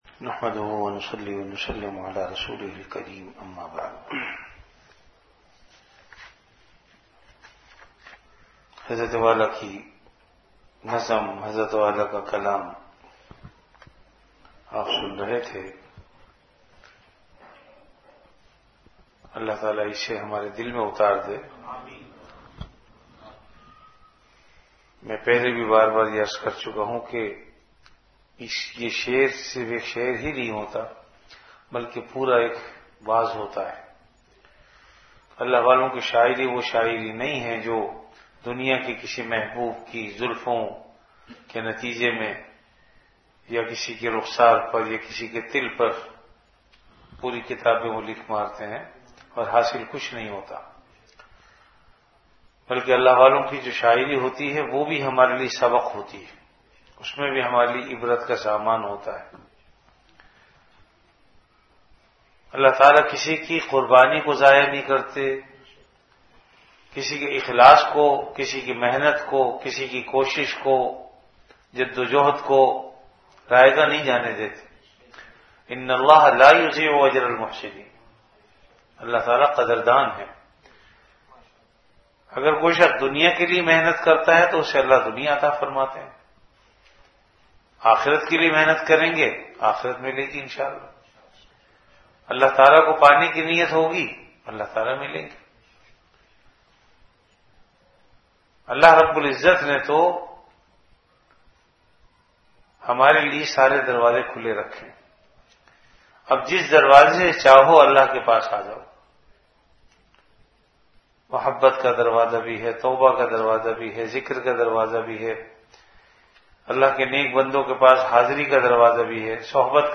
Delivered at Home.
Majlis-e-Zikr